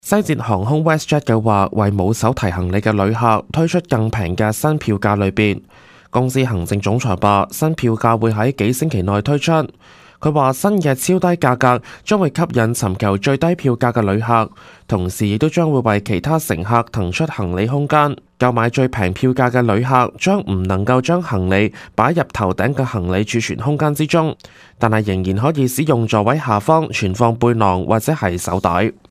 Canada/World News 全國/世界新聞
news_clip_19052.mp3